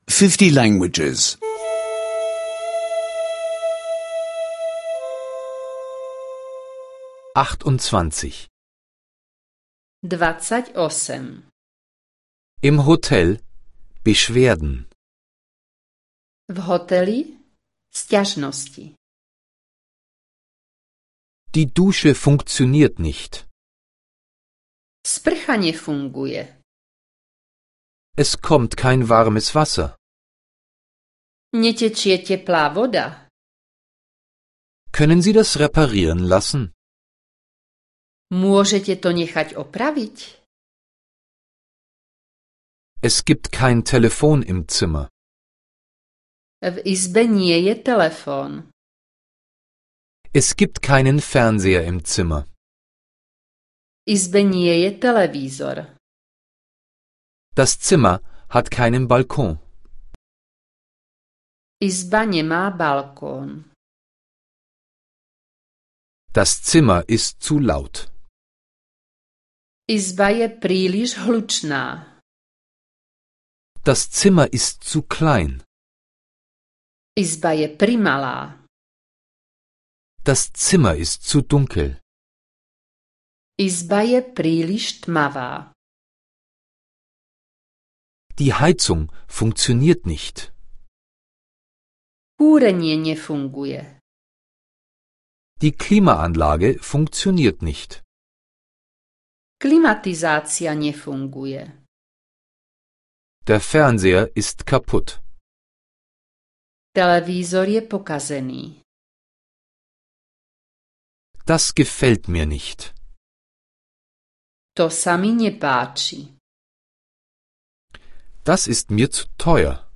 Slowakischer Audio-Lektionen, die Sie kostenlos online anhören können.